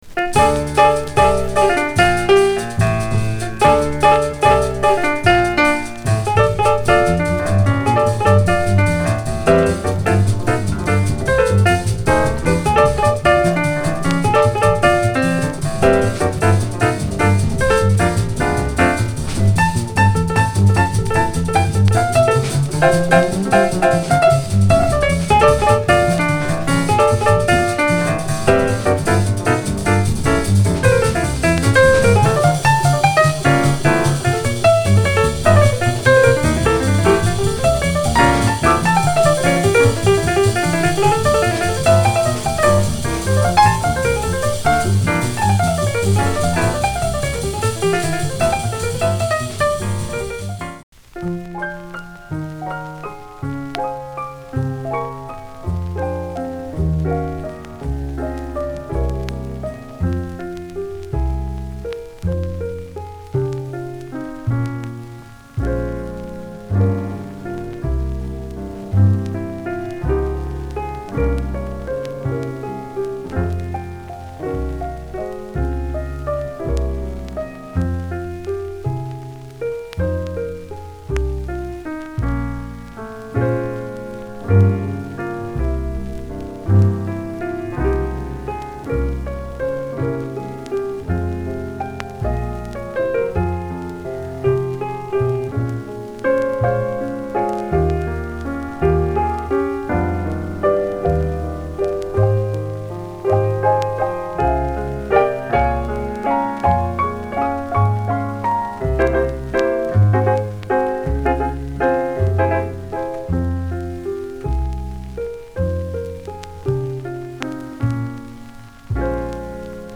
discription:Stereo黒トゲラベル